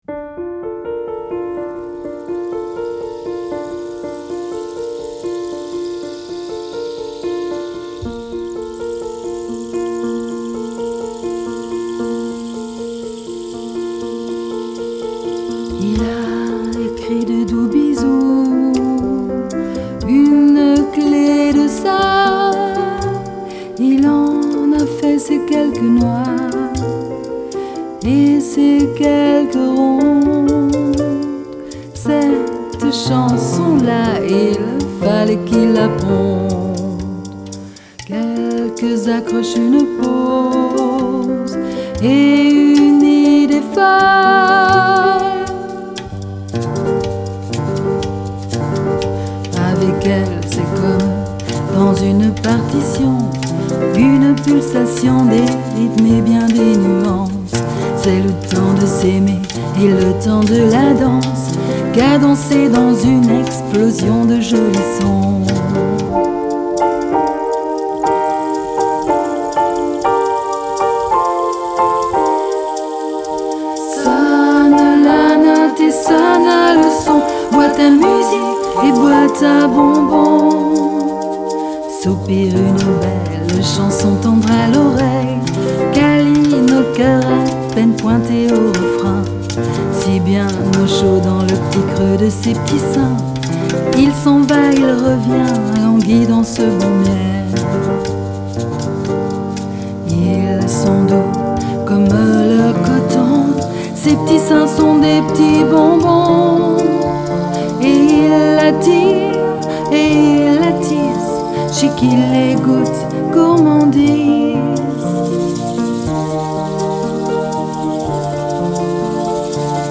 piano
diverses percussions